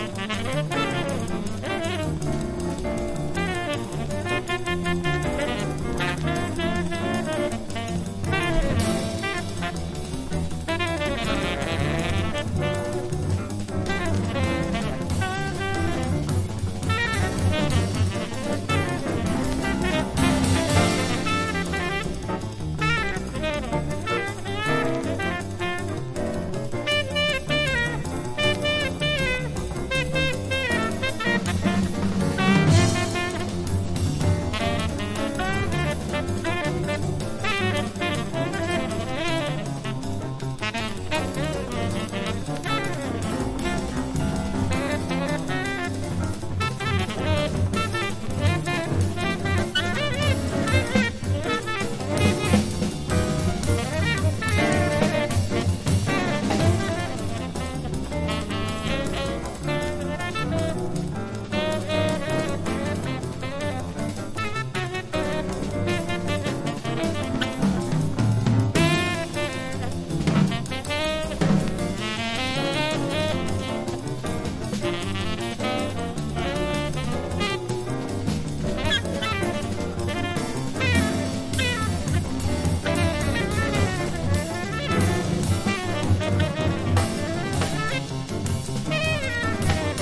• JAZZ
和ジャズ